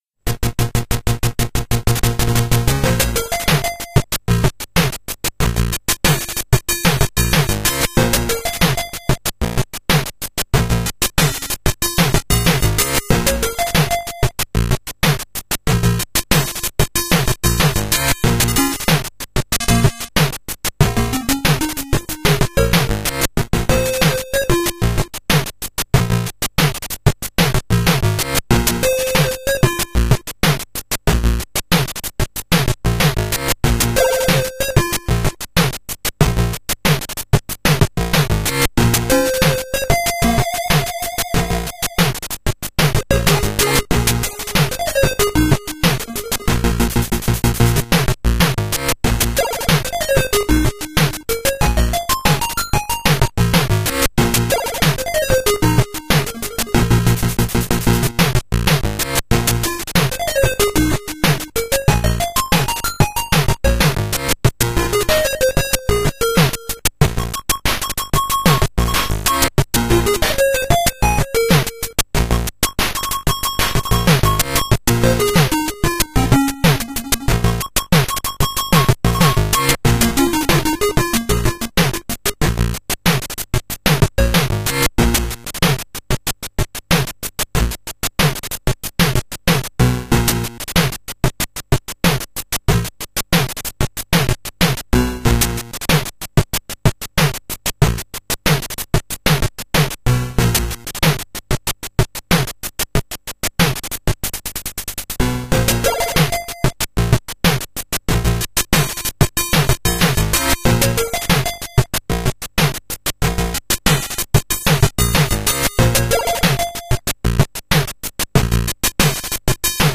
In-game music in OGG format (5.9 Mb)